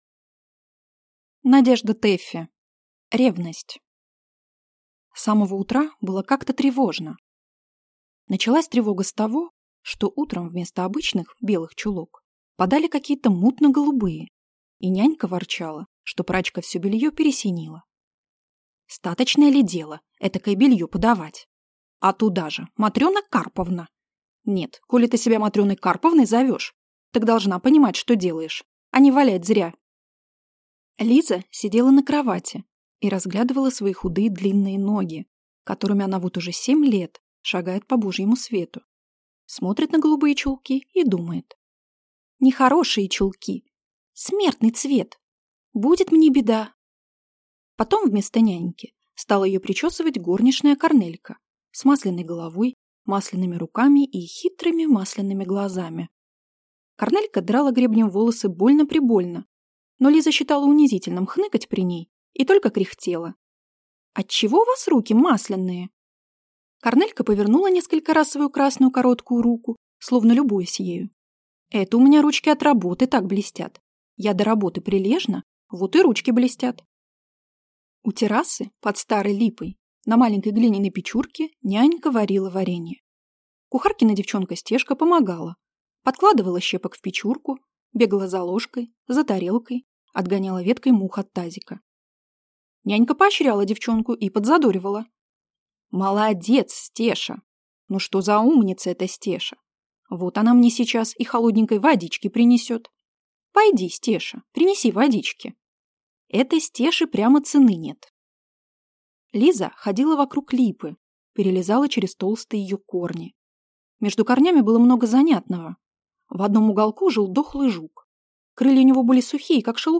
Aудиокнига Ревность